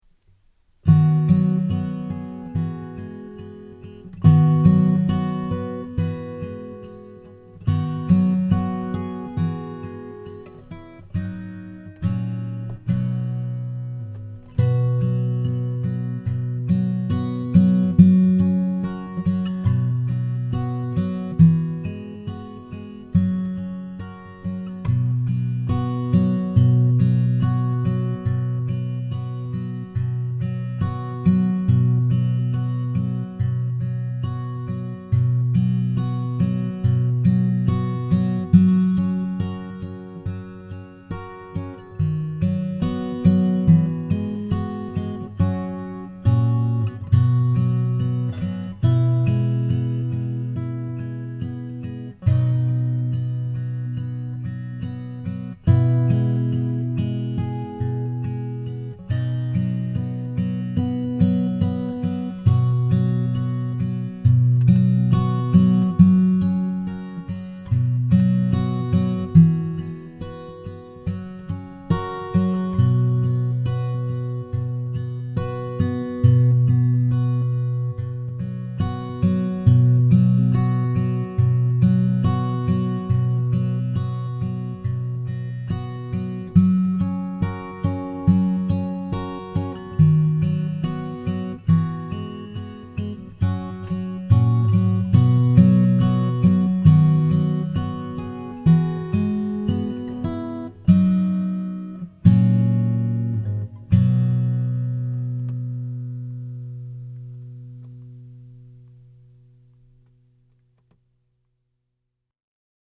I did just quick and easy arrangements for them with a rhythm and lead track. Just basic chords and melody.
So most of these arrangements are for two guitars, except for Silent Night.
Joy-to-the-World-BACKING-TRACK.mp3